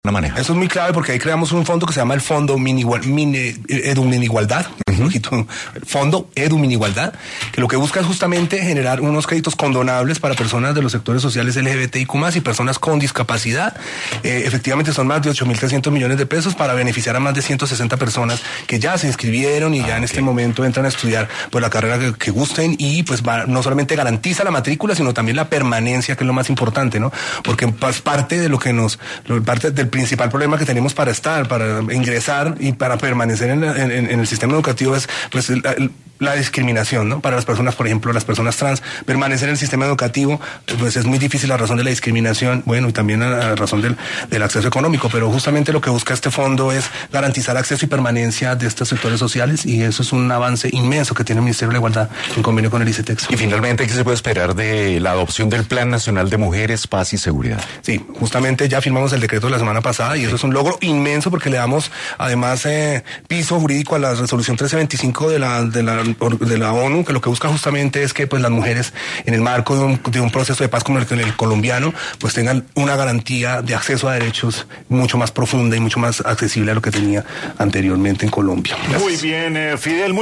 En Sin Anestesia, un programa de Caracol Radio, Florián habló sobre diversos temas, entre ellos, los programas creados por esta institución.